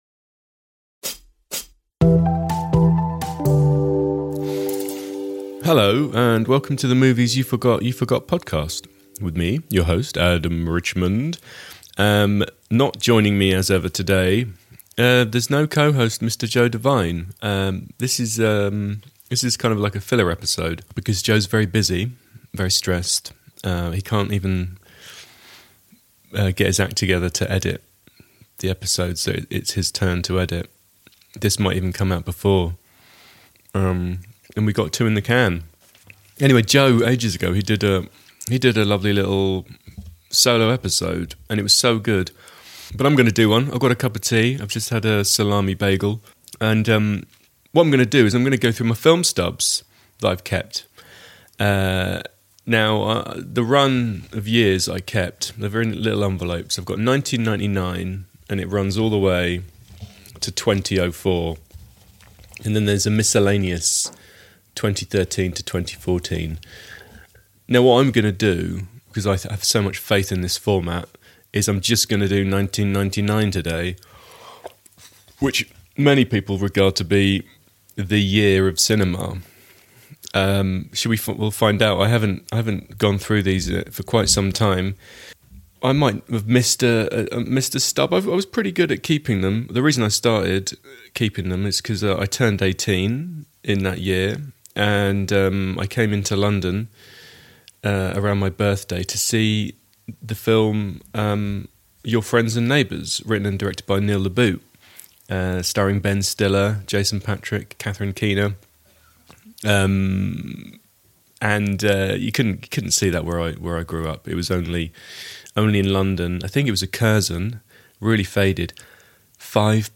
A man listing films and reflecting on where he sat and how much the ticket was.